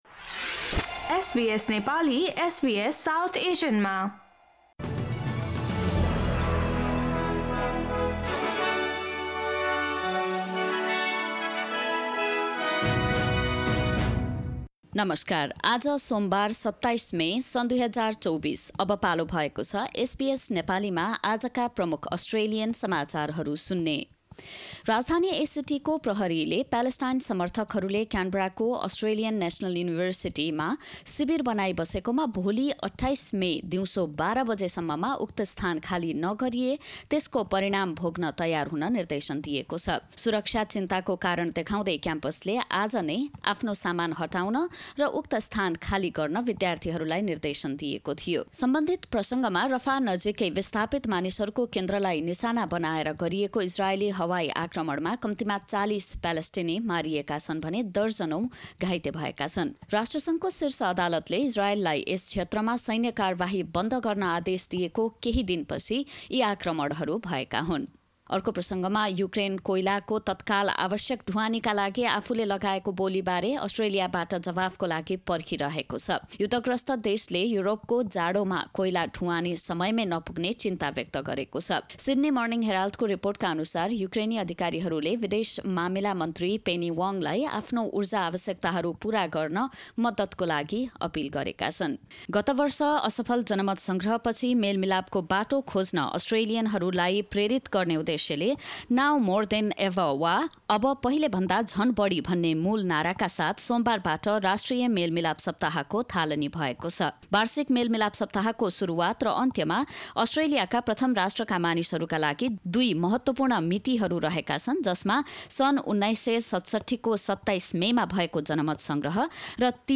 SBS Nepali Australian News Headlines: Monday, 27 May 2024